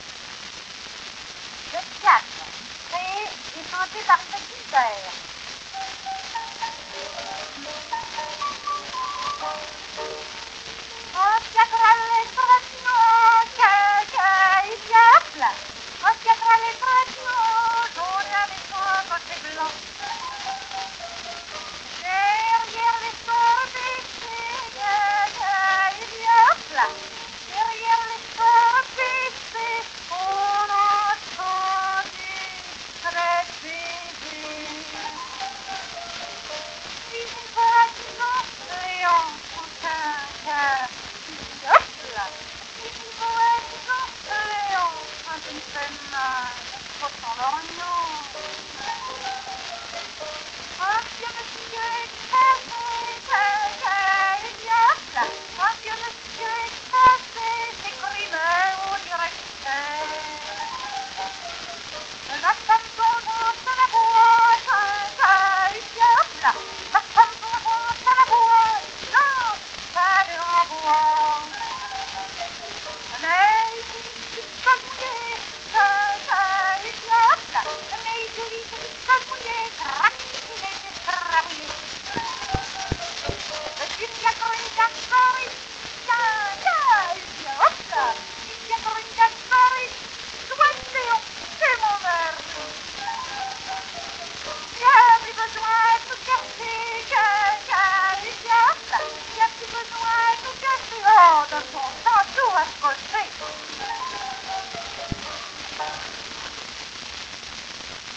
mit Klavierbegleitung